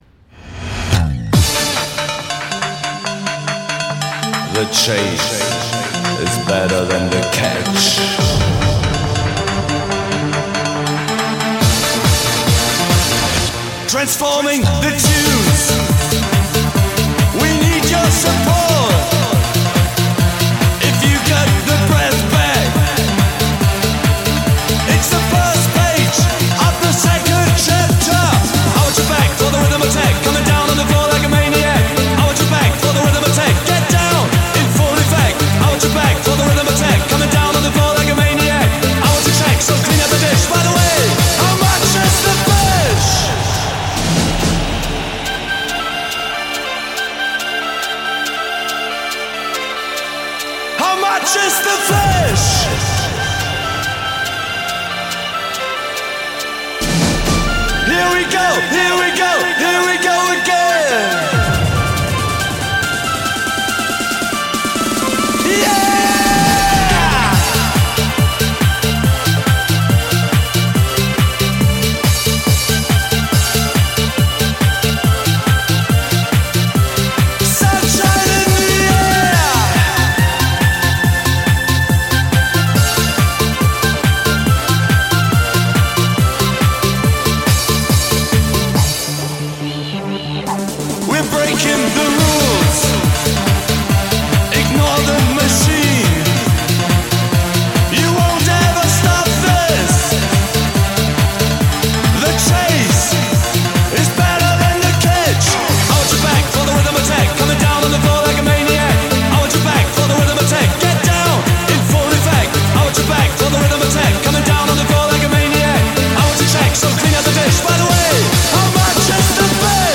Ретро музыка
музыка 90-х